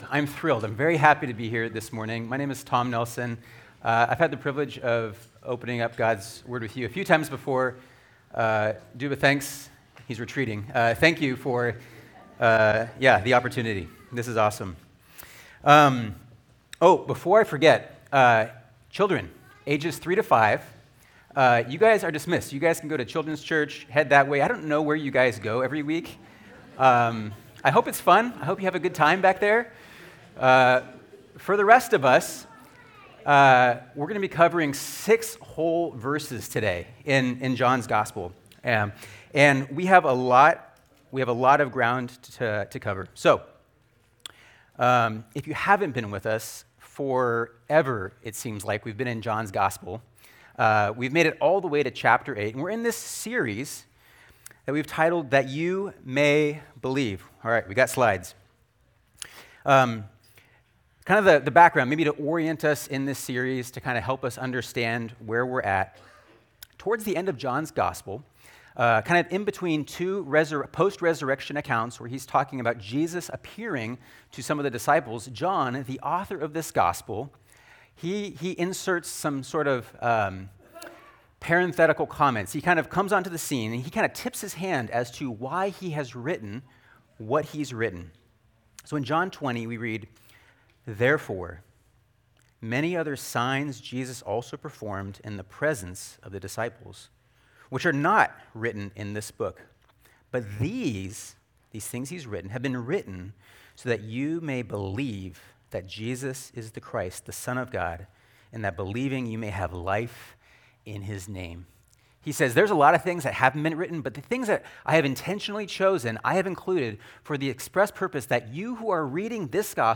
Sermon Notes:Coming soon.